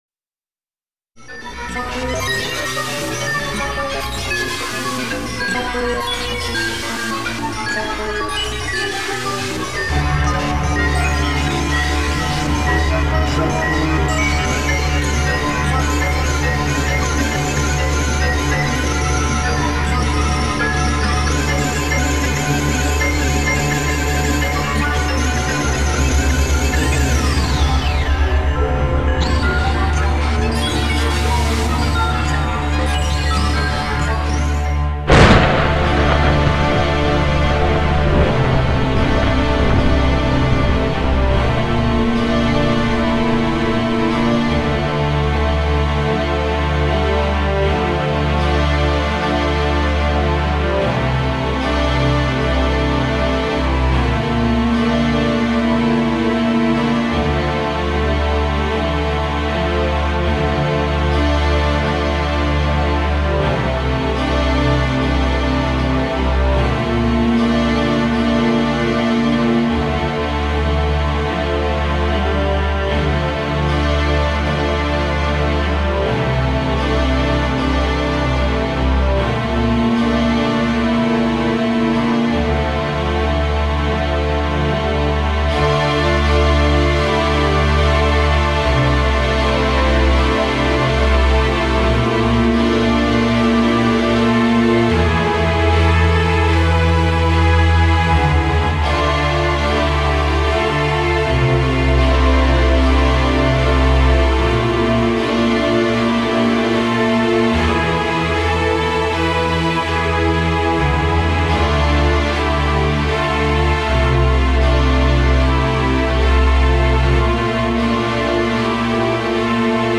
And they don't quite sound right in xmms.